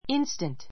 ínstənt